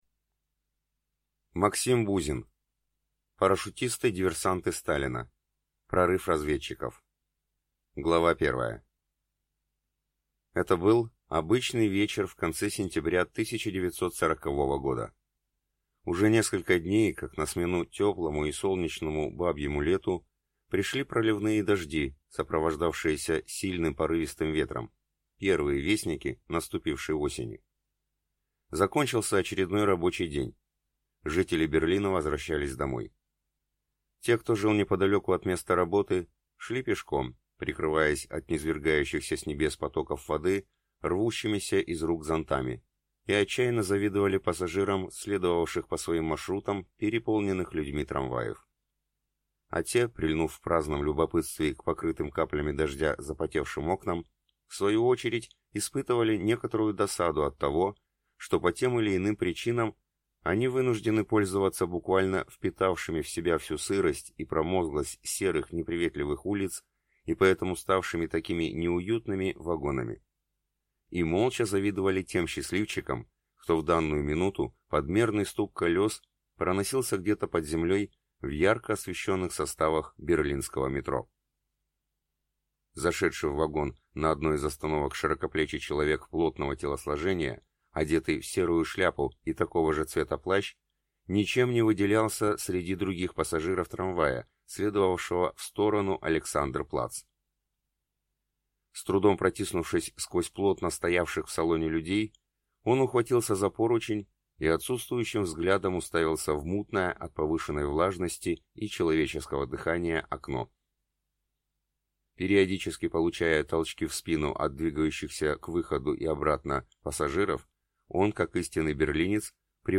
Аудиокнига Парашютисты-диверсанты Сталина.